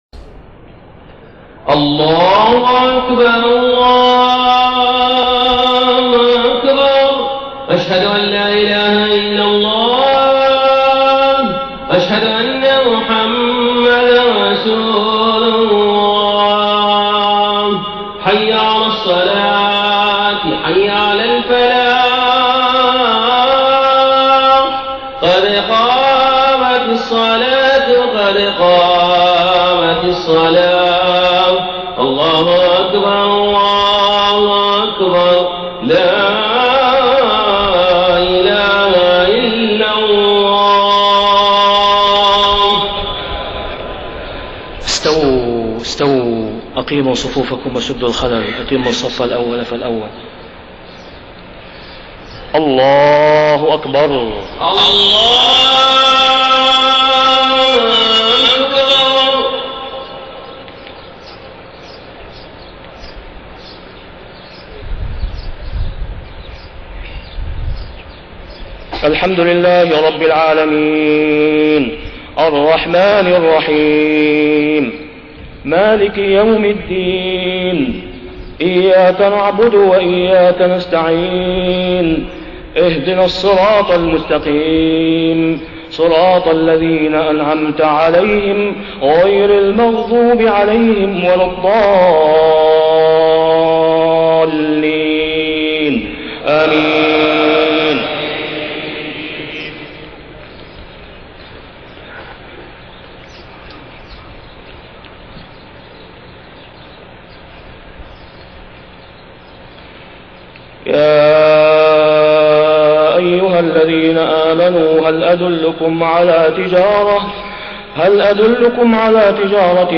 صلاة الجمعة 4 صفر 1430هـ خواتيم سورة الصف 10-14 > 1430 🕋 > الفروض - تلاوات الحرمين